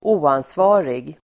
Uttal: [²'o:ansva:rig]